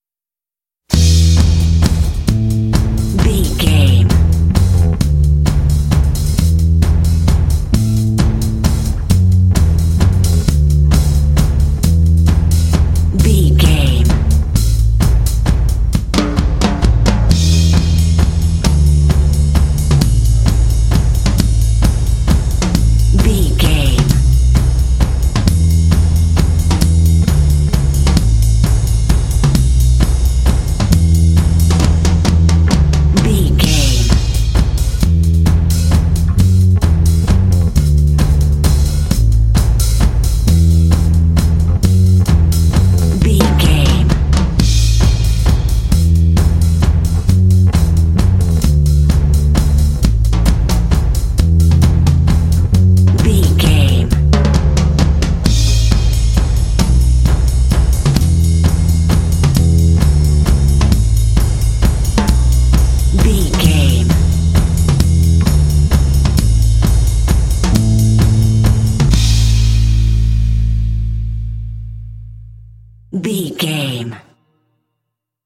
This soft rock music is ideal for main menu pages.
Ionian/Major
melancholy
fun
drums
electric guitar
bass guitar
soft rock